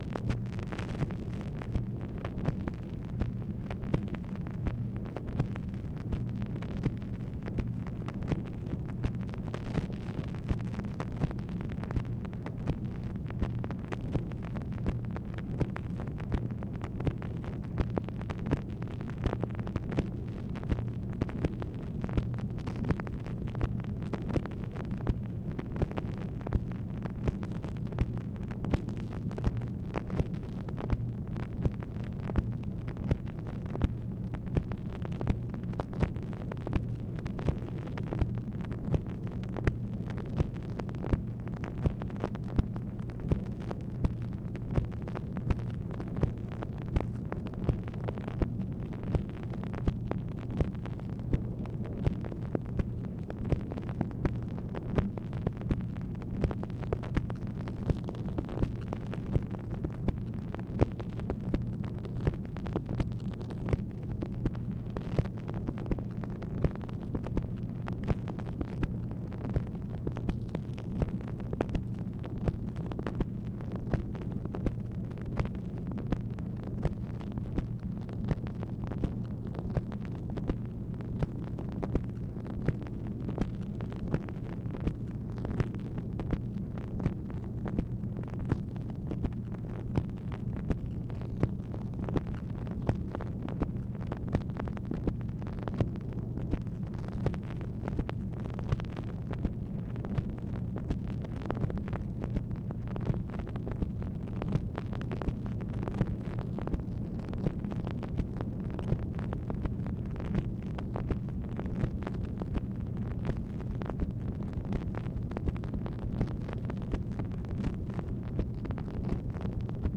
MACHINE NOISE, August 29, 1966
Secret White House Tapes | Lyndon B. Johnson Presidency